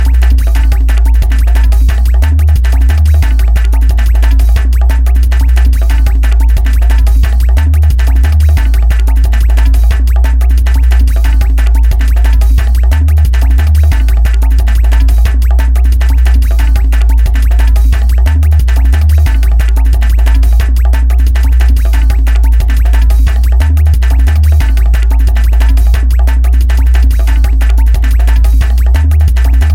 Instrumental Mix